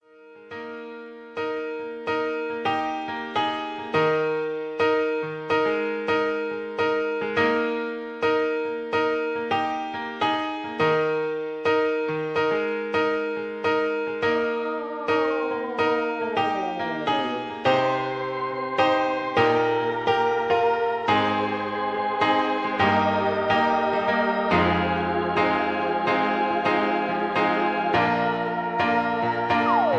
karaoke, mp3 backing tracks
rock, classic music, middle of the road